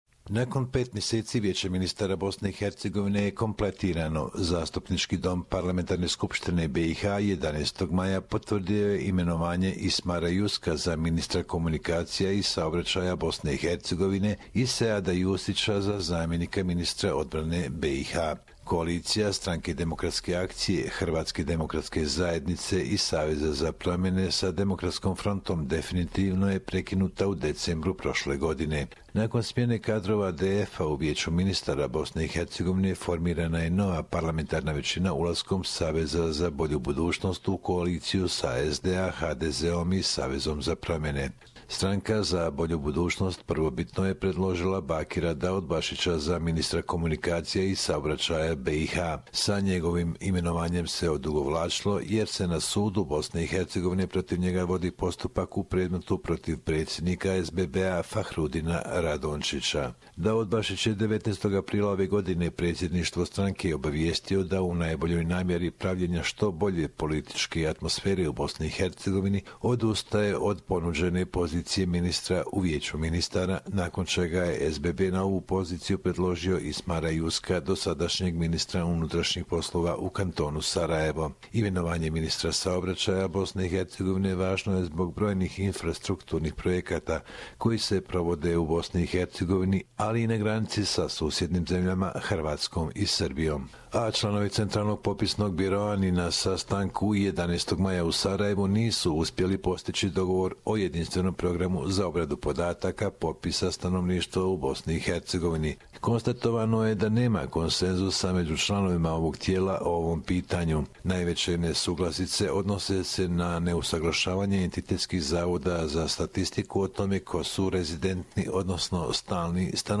report from Bosnia and Herzegovina